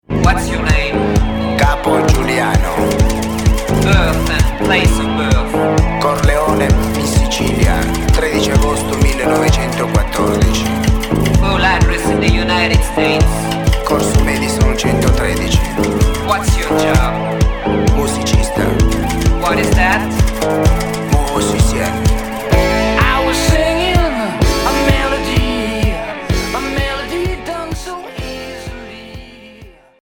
Groove rock